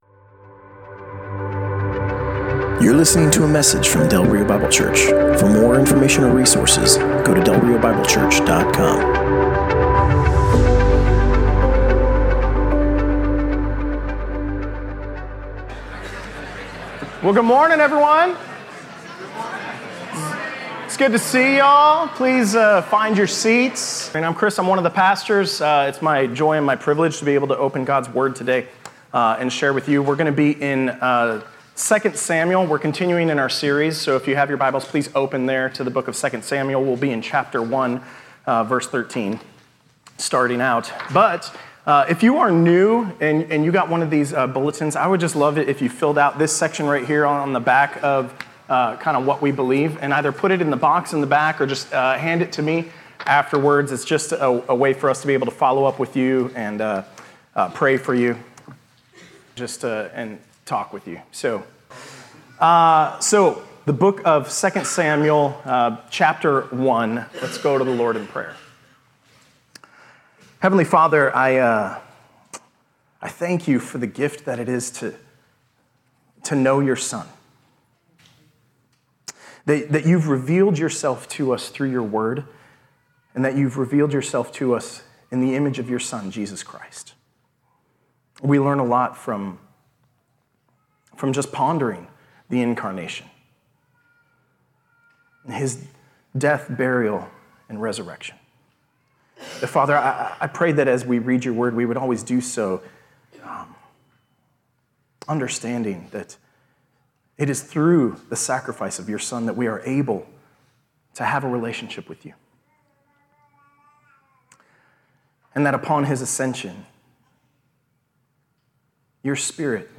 Passage: 2 Samuel 1: 13-27 Service Type: Sunday Morning